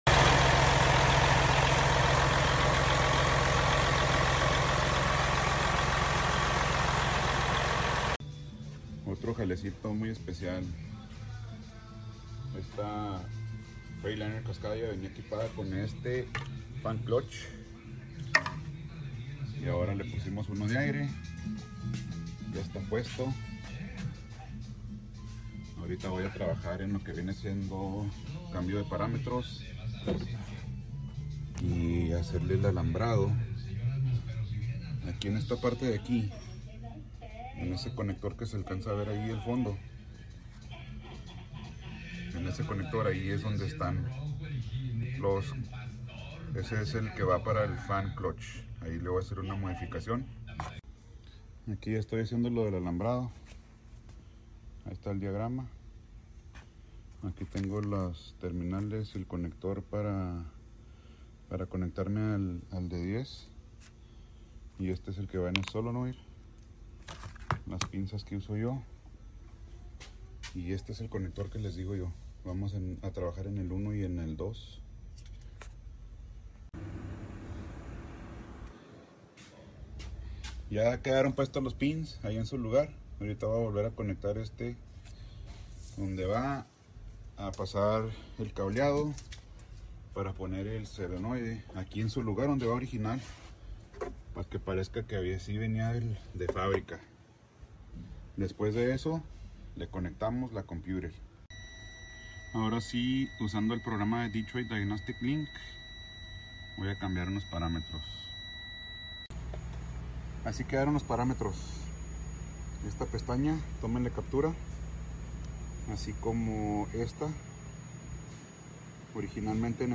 Freightliner Cascadia DD15 variable speed sound effects free download